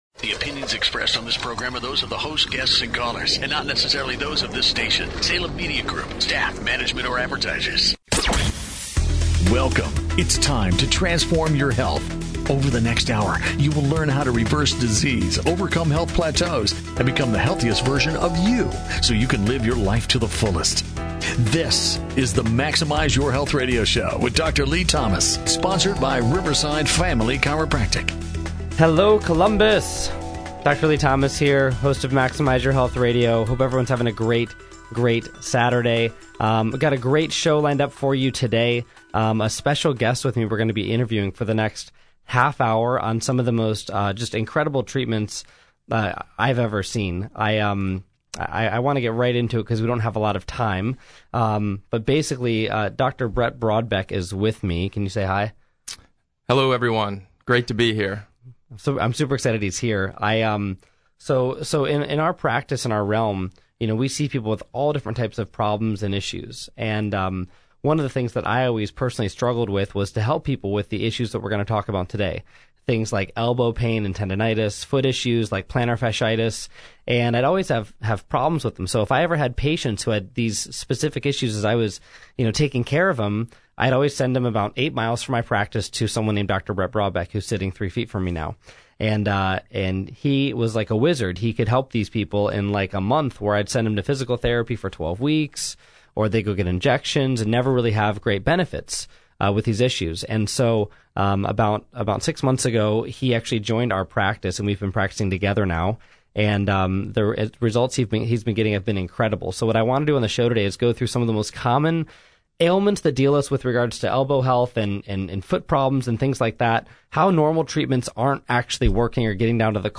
[RADIO INTERVIEW]
In this Maximize Your Health radio show, you’ll learn about today’s most prevalent trends around EPAT (Extracorporeal Pulse Activation Technology) as a treatment option, including how it helps your patients and create better outcomes for your practice.